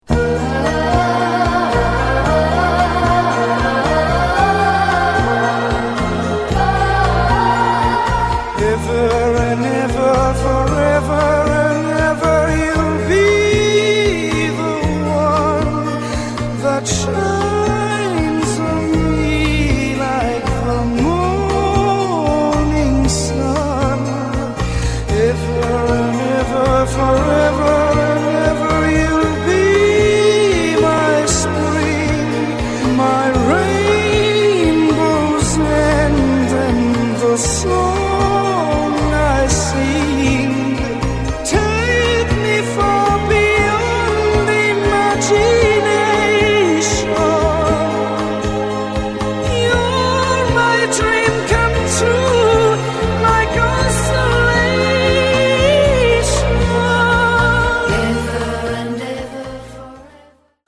Senza bassi, un vibrato stretto stretto...